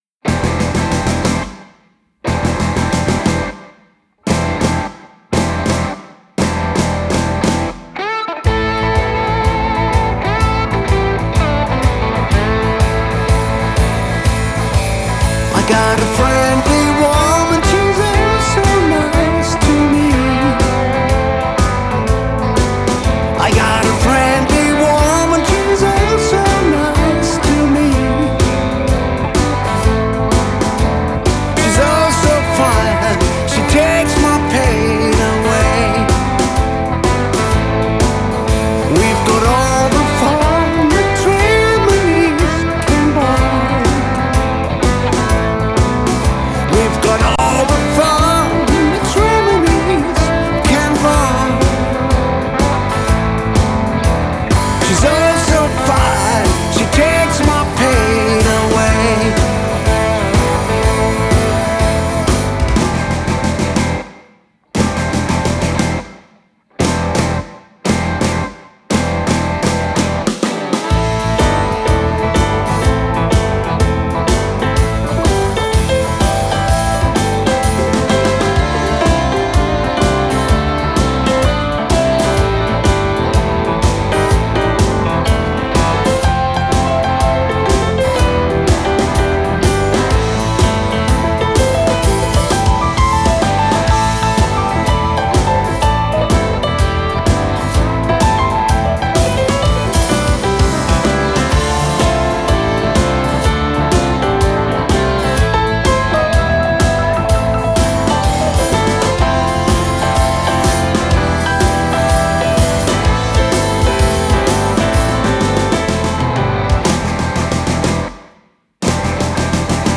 schnörkellosen, geradlinigen, handgemachten Blues-Rock
stehen für heavy Blues und straight Rock‘
Guitar, Vocals
Drums
Bass
Trumpet